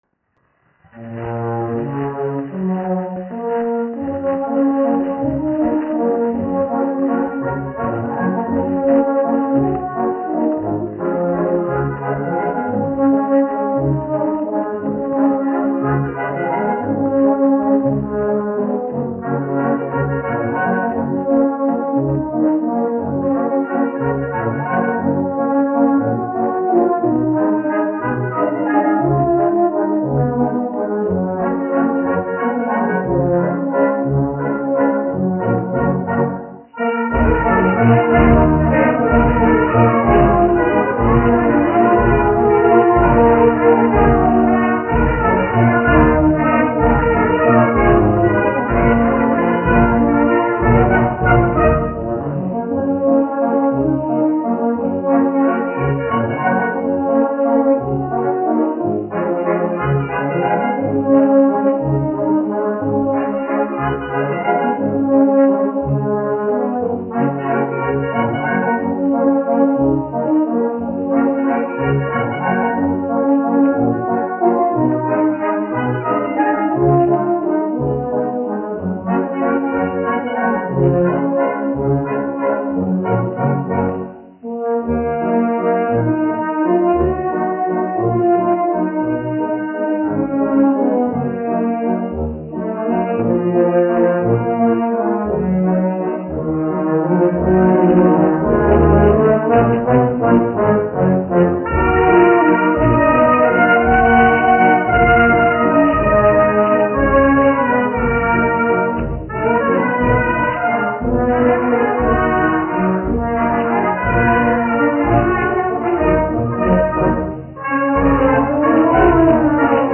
1 skpl. : analogs, 78 apgr/min, mono ; 25 cm
Pūtēju orķestra mūzika
Skaņuplate